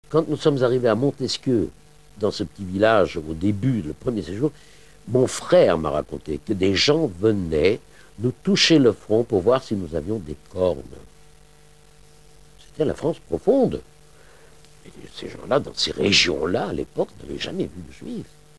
Témoignages de survivants.